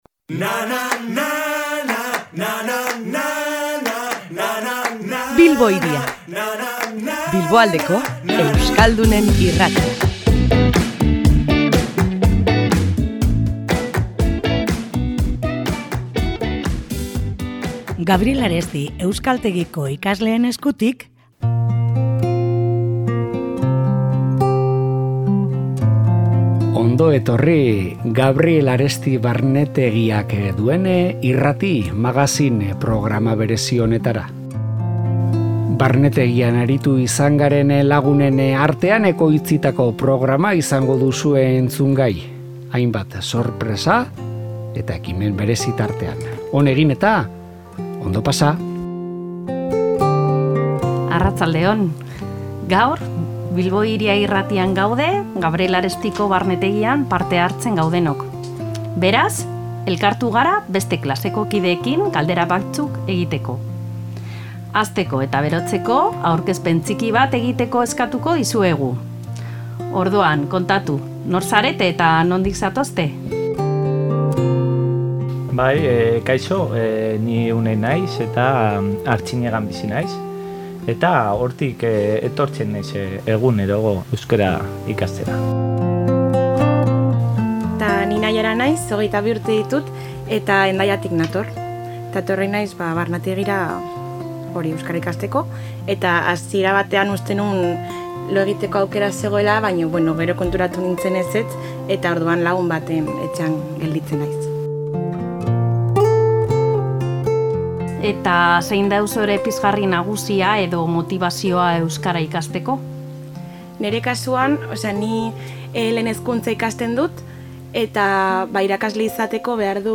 Irratia ere bisitatu dute eta programa zoro-berezia sortu: elkarrizketak, diasporako lagunak, notizia eroak, iritzia, musika, artea, irakurketa dramatizatuak… horiek guztiak entzungai, euskara ikasleen ahotan.